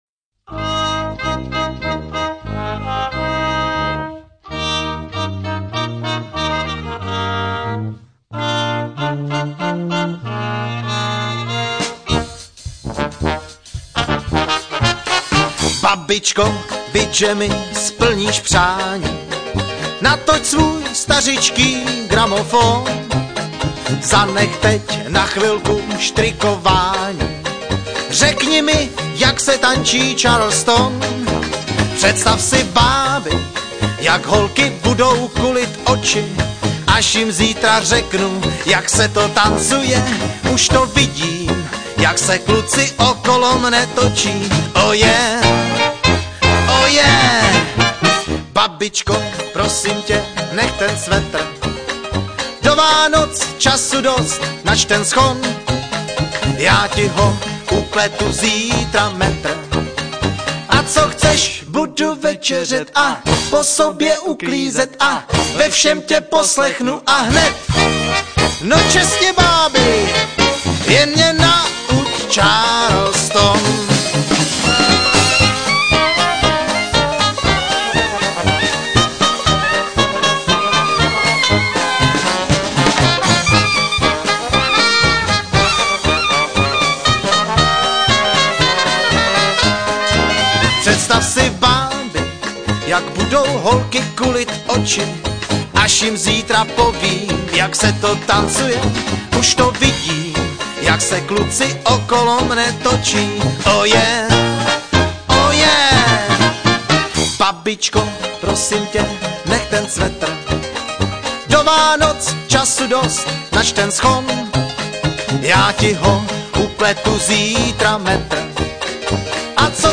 Nahráno živě, 12.2.2000 v Českých Budějovicích